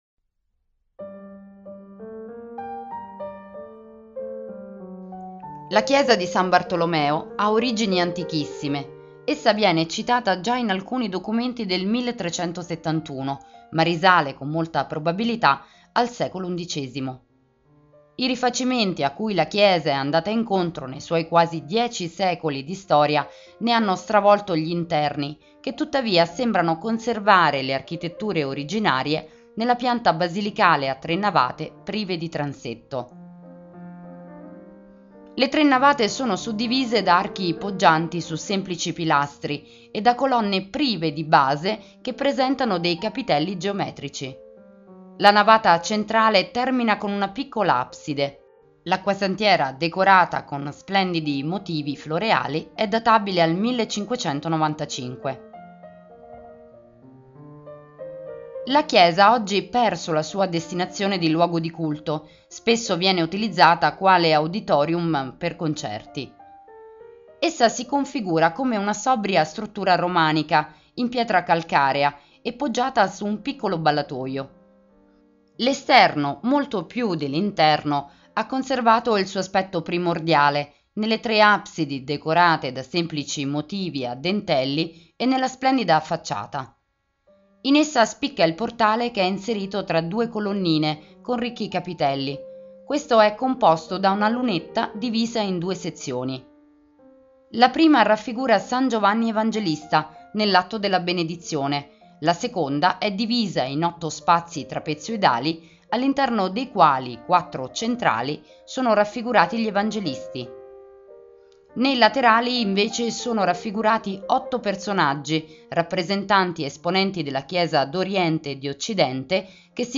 Audioguida Campobasso - Chiesa di San Bartolomeo - Audiocittà